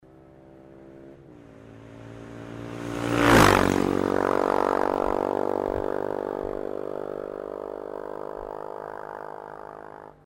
Klicka för att höra BMW GS650 flyga förbi
BMWF650CS.mp3